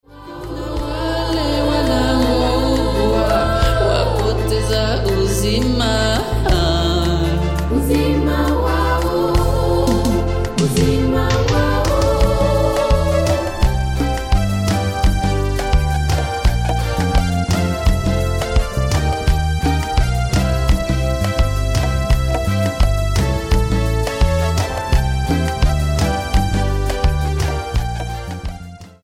STYLE: World